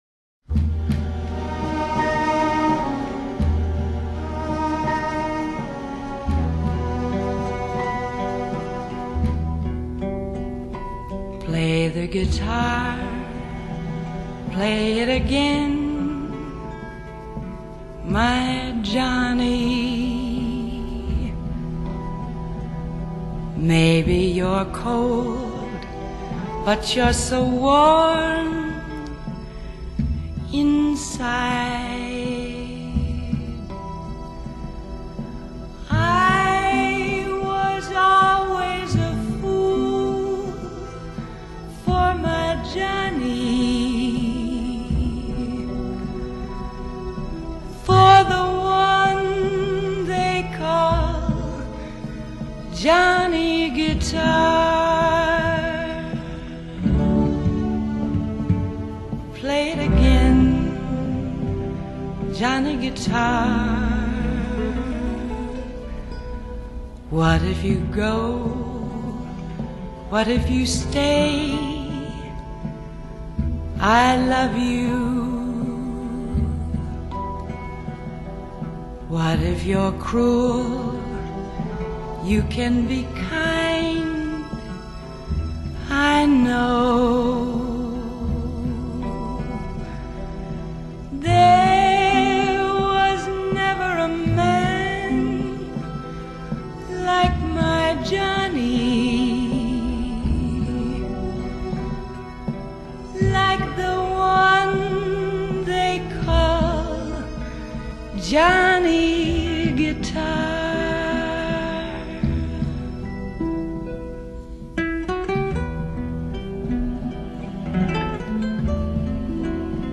Original recordings | Genre: Vocal, jazz, latin jazz